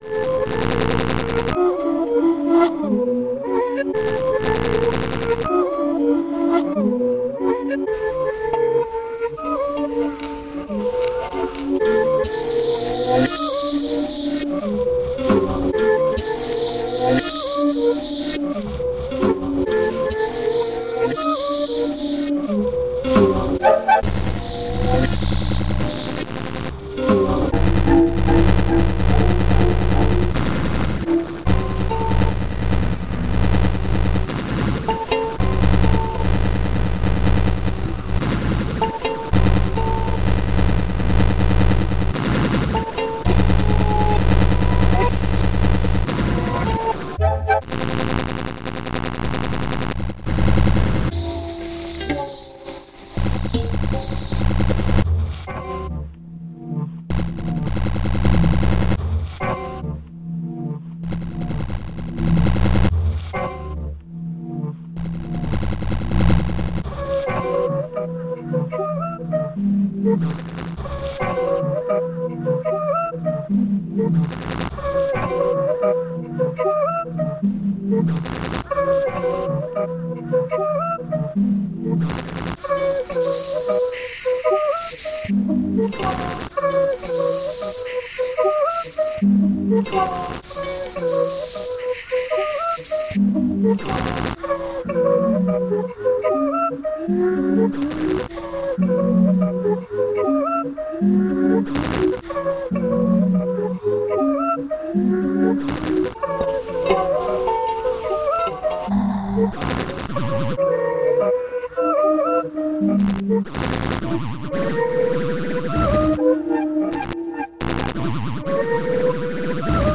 Hascillators, BoxHarp, Bowl, Etc